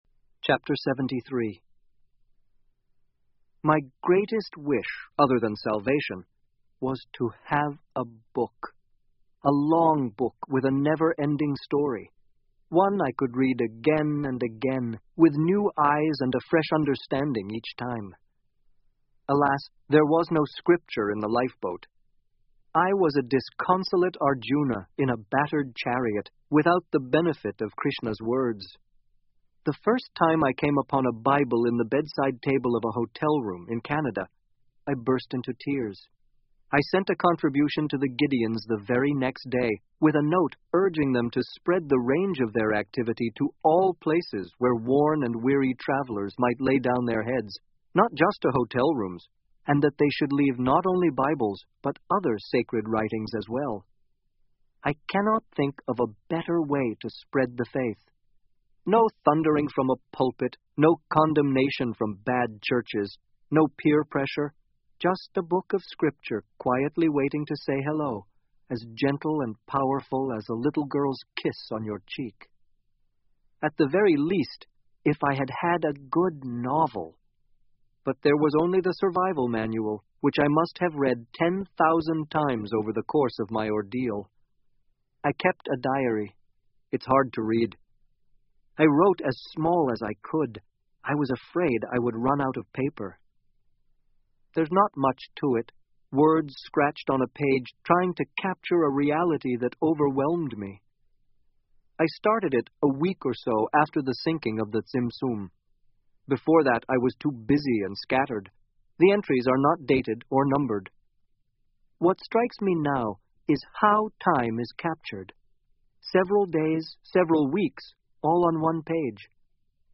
英文广播剧在线听 Life Of Pi 少年Pi的奇幻漂流 06-14 听力文件下载—在线英语听力室